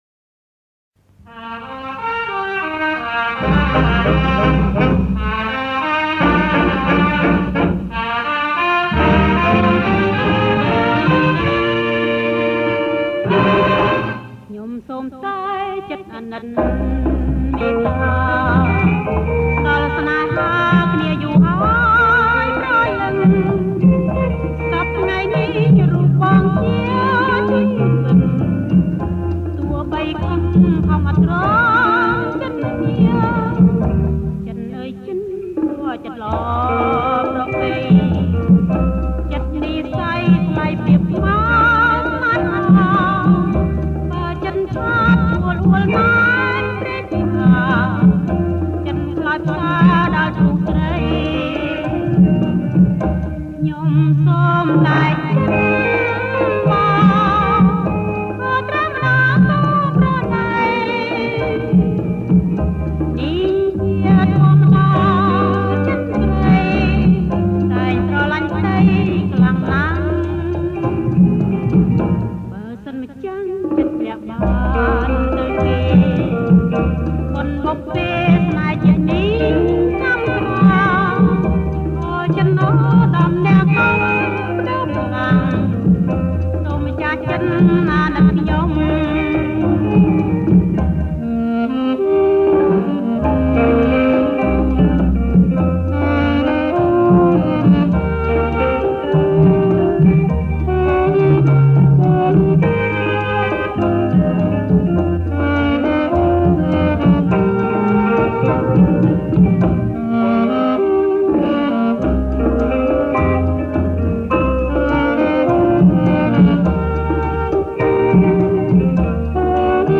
• ប្រគំជាចង្វាក់ Rumba